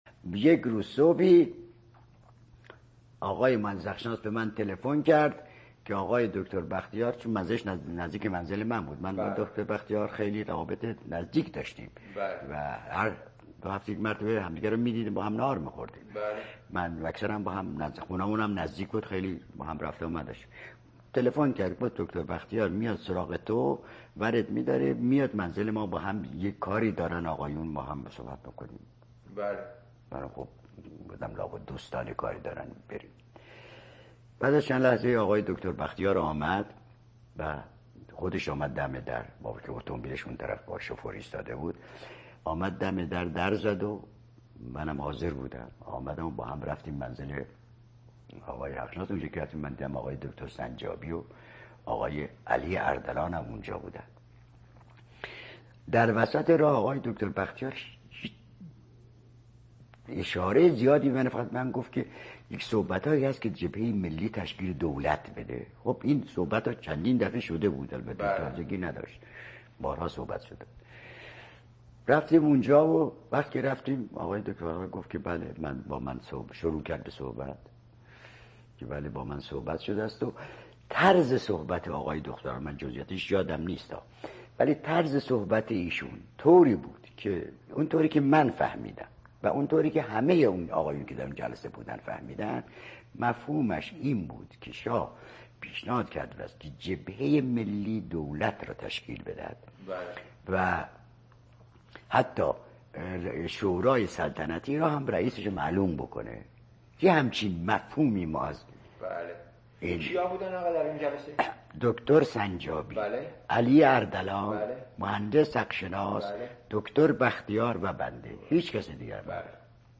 مصاحبه با مهندس احمد زیرک زاده – دقایق پایانی کاست ۲ طرف دوم نوار – مصاحبه در سال ۱۹۸۶ در شهر آرلینگتون، آمریکا